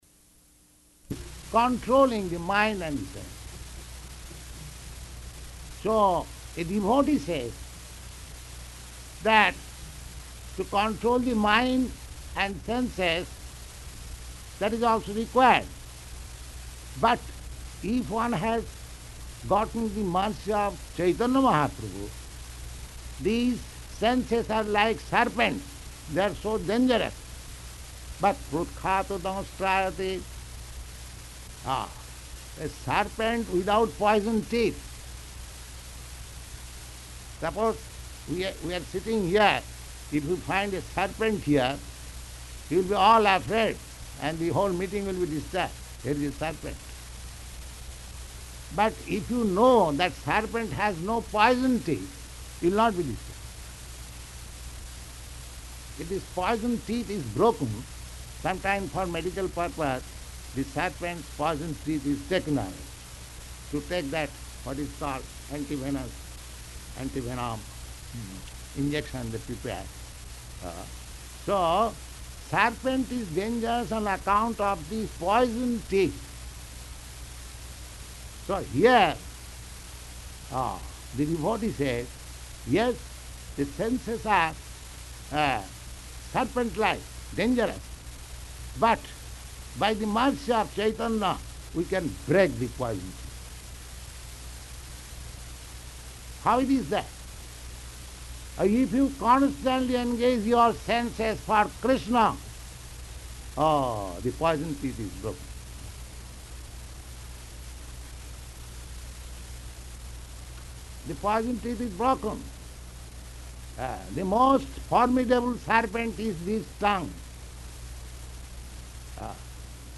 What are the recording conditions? Lecture [partially recorded] Location: Los Angeles